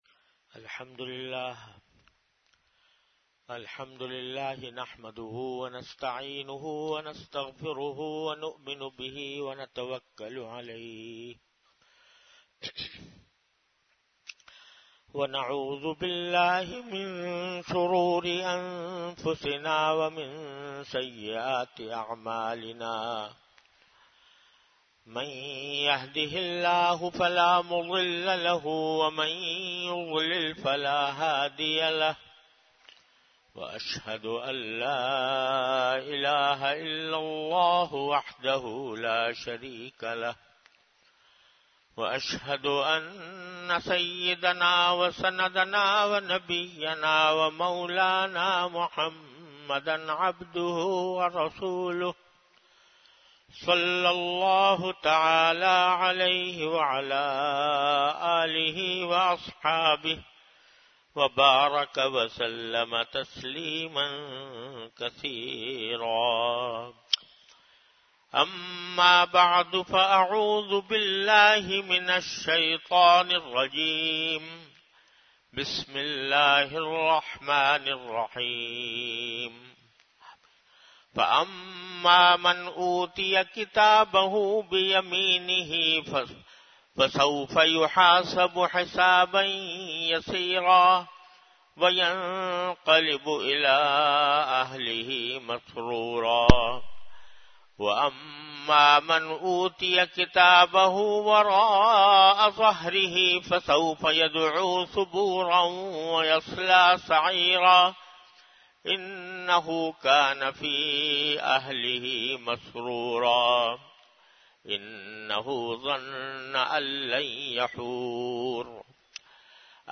An Islamic audio bayan by Hazrat Mufti Muhammad Taqi Usmani Sahab (Db) on Tafseer. Delivered at Jamia Masjid Bait-ul-Mukkaram, Karachi.